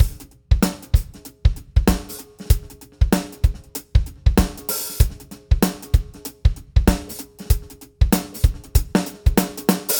ハイハット
最後に、シャンシャンと高い音でリズムに華を添えるのが、2枚のシンバルが重なってできている「ハイハット」です。
ハイハットは重みがなく軽やかなサウンドをしていて、弱く叩けば目立たないので、たいていの場合はキックやスネアよりも細かい刻みで演奏されます。
r1-essentials-hat.mp3